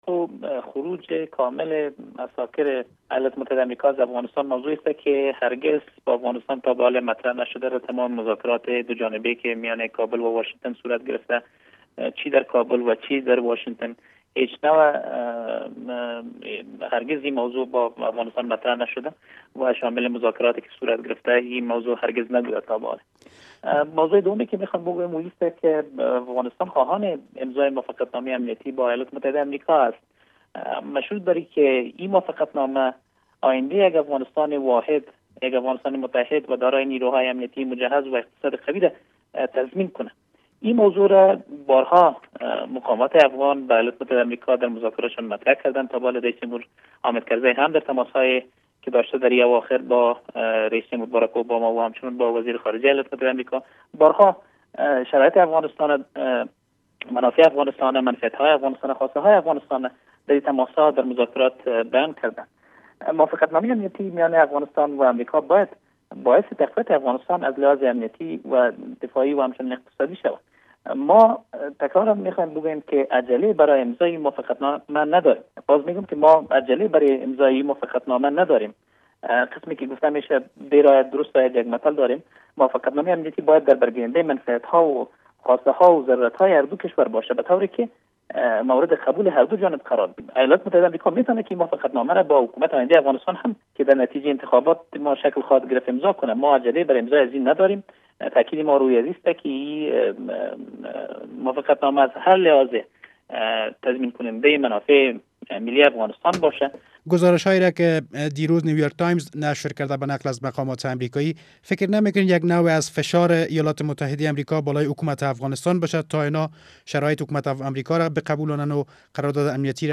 مصاحبه با ایمل فیضی